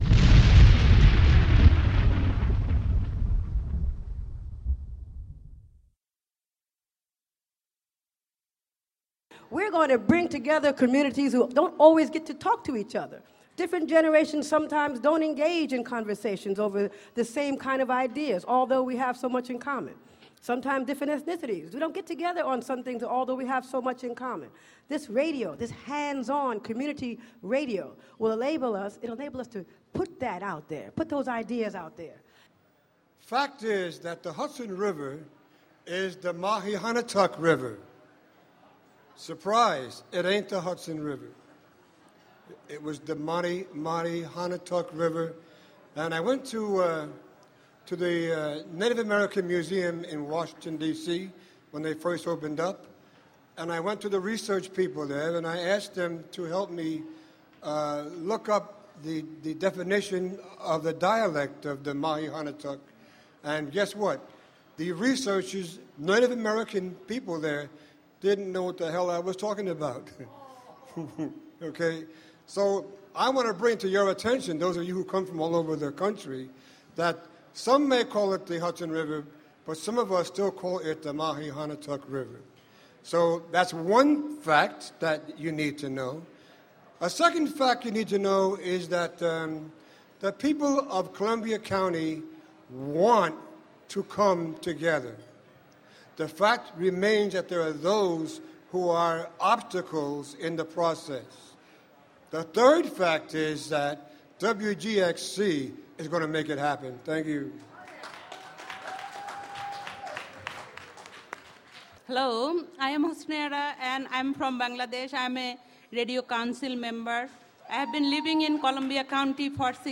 Members of the Columbia-Greene County African-American, Haitian, Bangladeshi, Latino and white communities express their hopes and aspirations for WGXC during the Barnraising Plenary at St. Mary's School in Hudson Friday, September 24, 2010.